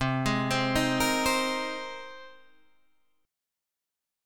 Cm7#5 chord